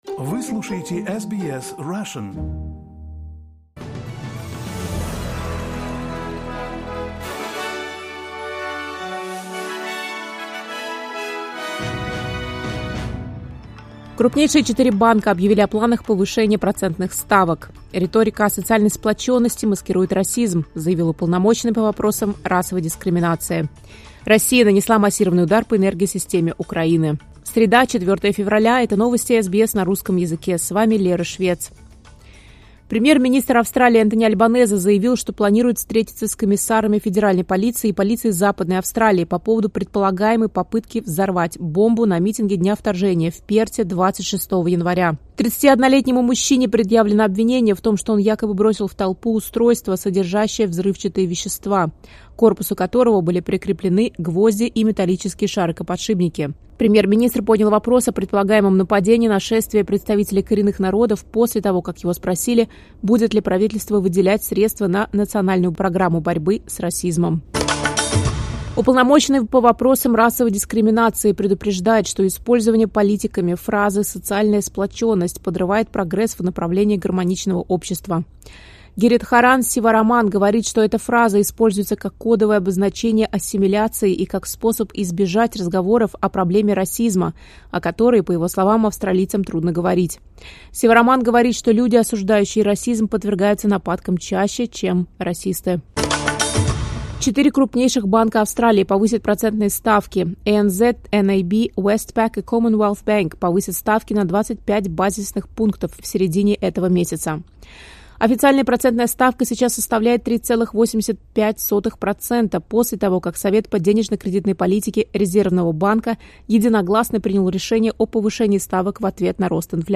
Новости SBS на русском языке — 04.02.2026